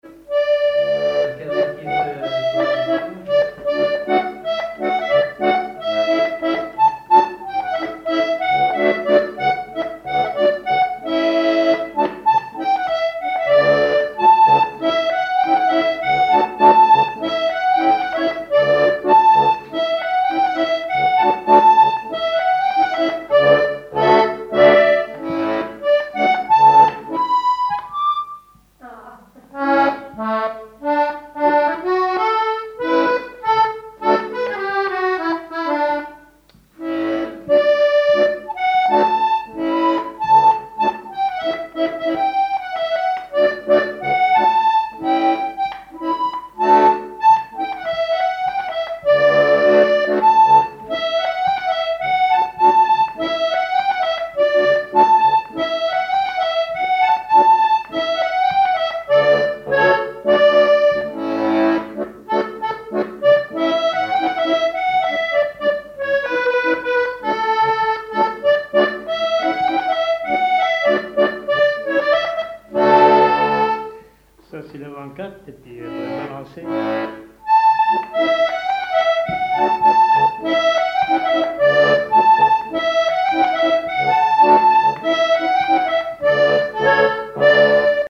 Saint-Hilaire-de-Riez
danse : quadrille : avant-quatre
accordéon diatonique
Pièce musicale inédite